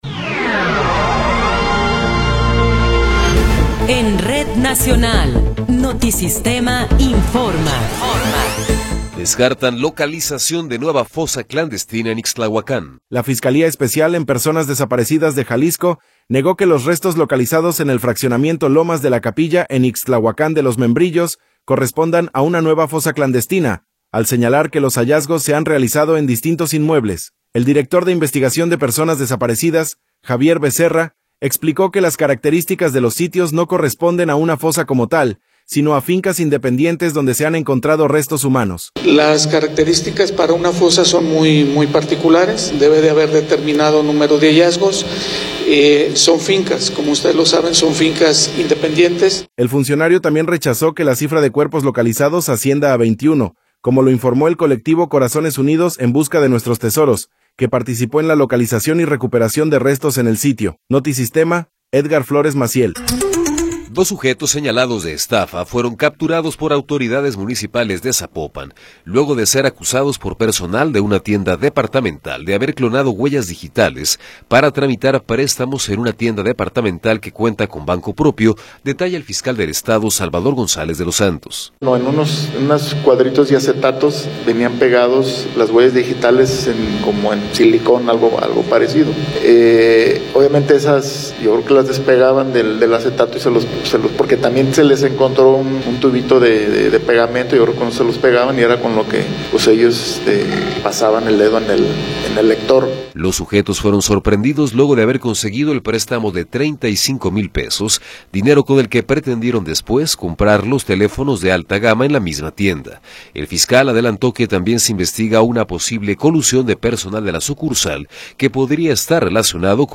Noticiero 12 hrs. – 23 de Abril de 2026
Resumen informativo Notisistema, la mejor y más completa información cada hora en la hora.